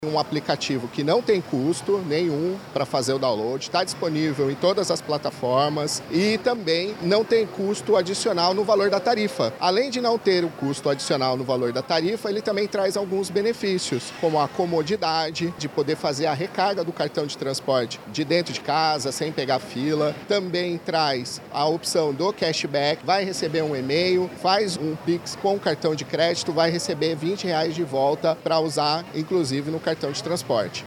SONORA-2-RECARGA-PASSA-FACIL-.mp3